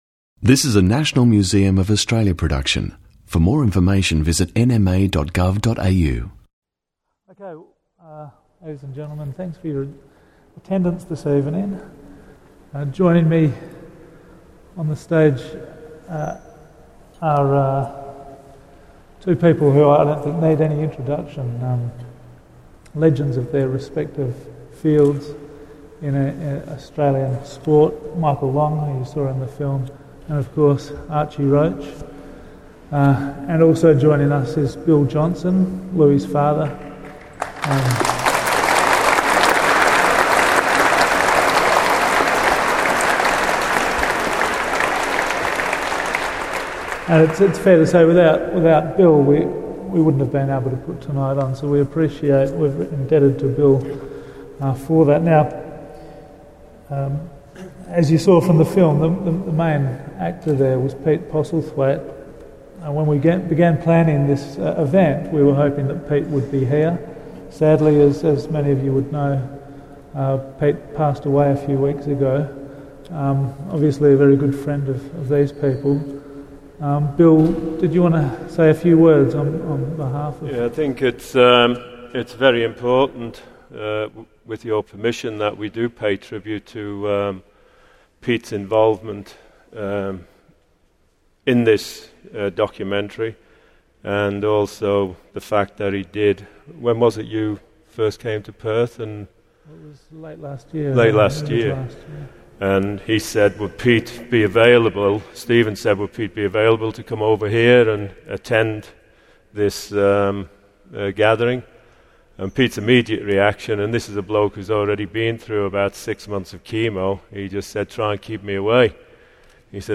Question and answer session with Archie Roach and friends | National Museum of Australia
answer questions from the audience following the screening of the film Liyarn Ngarn